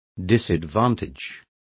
Complete with pronunciation of the translation of disadvantages.